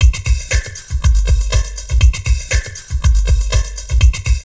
Index of /90_sSampleCDs/USB Soundscan vol.07 - Drum Loops Crazy Processed [AKAI] 1CD/Partition B/07-120FLUID